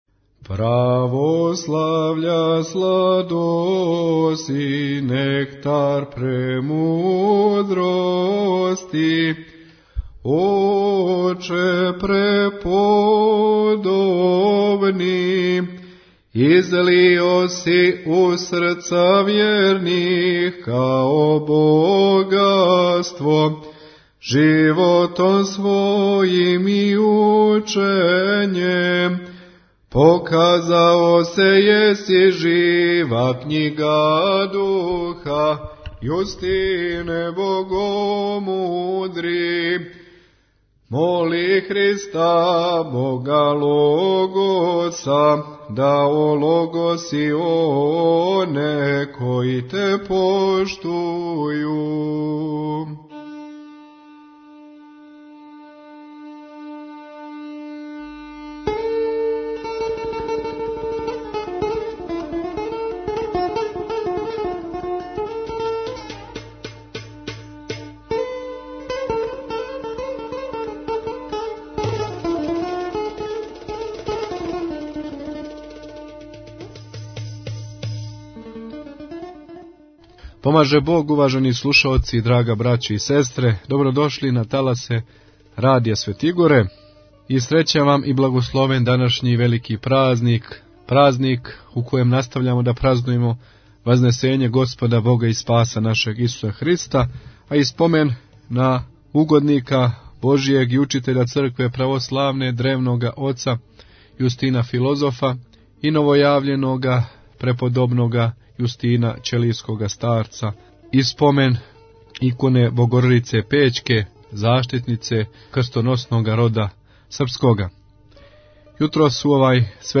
Архиепископ цетињски Митрополит црногорско-приморски Господин Амфилохије служио је јутрос са свештенством Свету архијерејску литургију у Цетињском манастиру.
У литургијској проповиједи Владика Амфилохије је рекао да није случајно што је за преподобног Јустина Ћелијског врховни критеријум и мјера свега био Сам Богочовјек.